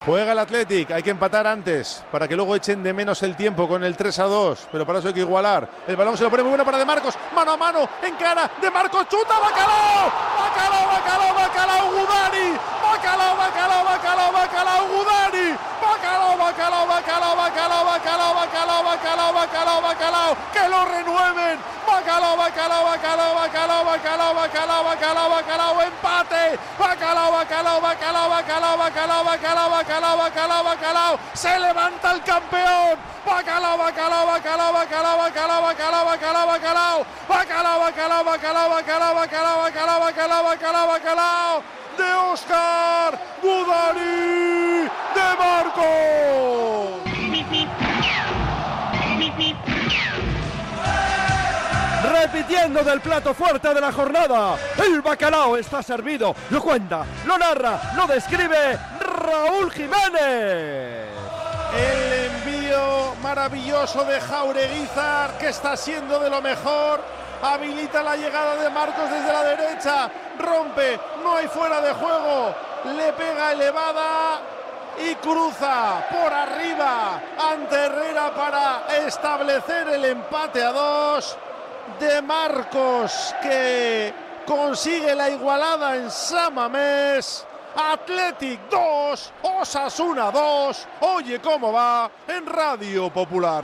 Así lo vivimos en La Emoción del Bacalao.